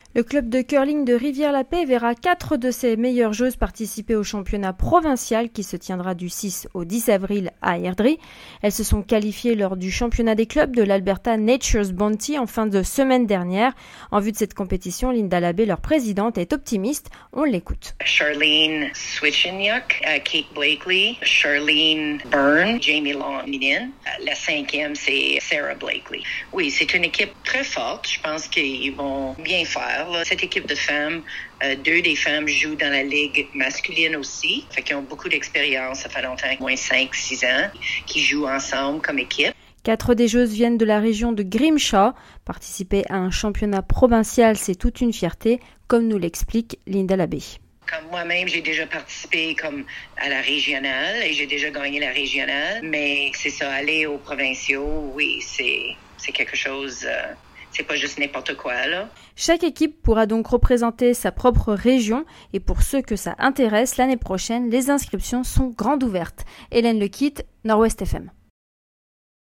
C'est un reportage